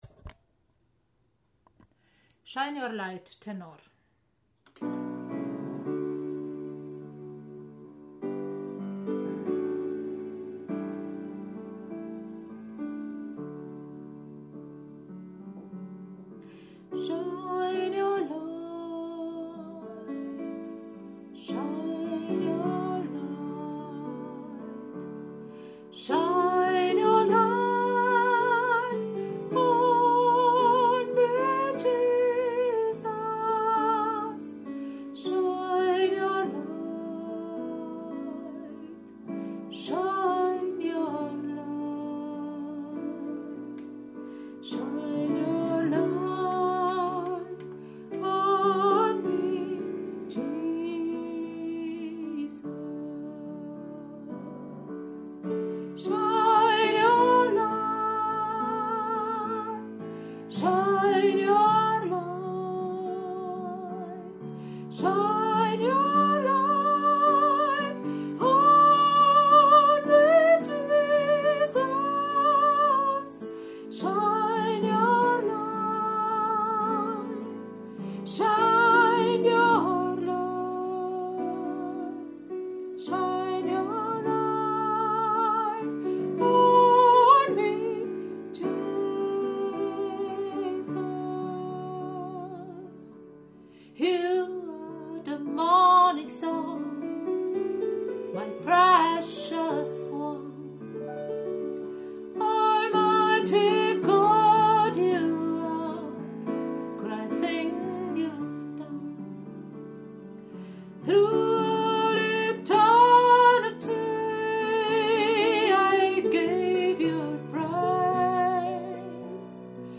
04 - Tenor - ChorArt zwanzigelf - Page 4